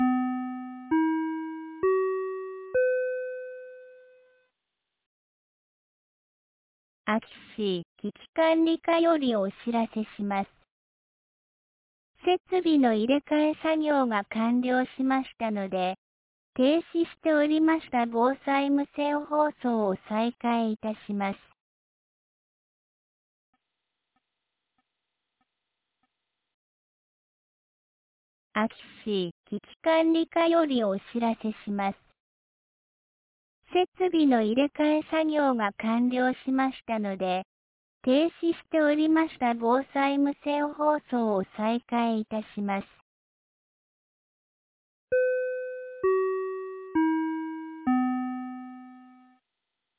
2025年02月21日 18時40分に、安芸市より全地区へ放送がありました。